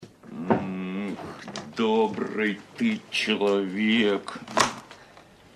Звуки цитат из фильма
В подборке — короткие, но узнаваемые фразы из популярных кинокартин, подходящие для установки на звонок, использования в мемах или видеороликах.